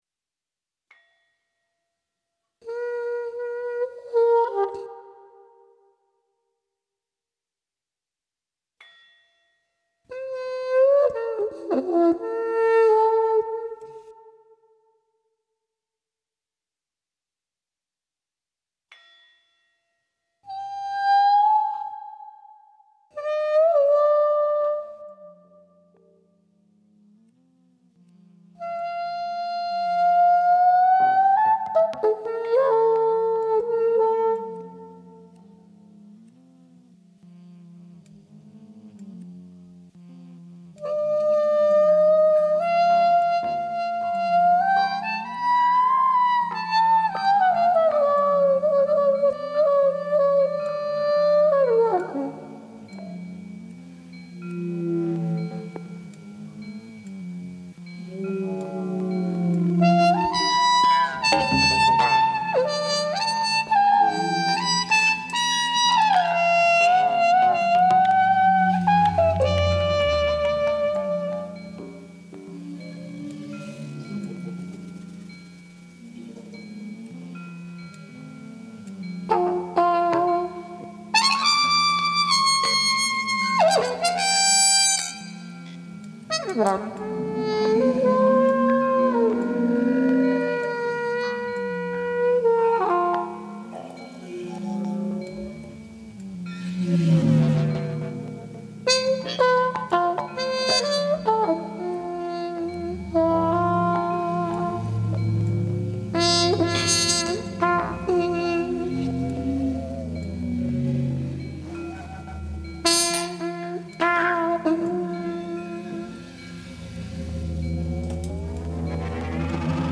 Listen to the interview to find out more details about how this process went and what it meant to the performers in the moment.